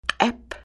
Listen to the elders